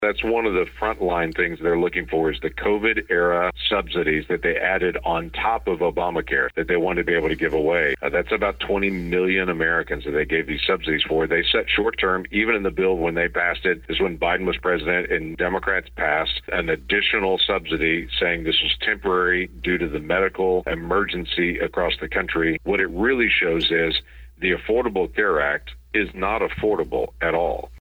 US Senator Lankford called into Bartlesville Radio for our monthly podcast to talk about the government shutdown.